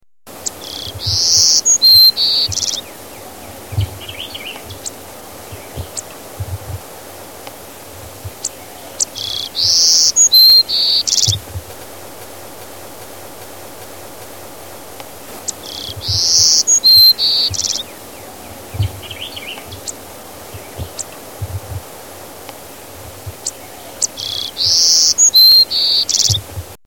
ENDANGERED SPECIES (Golden-Cheeked Warbler)
Your best bet for seeing a GCW on the property is to listen to the above song file until you know the GCW call, then keep your ears open when you are outside.
golden-cheeked warbler.mp3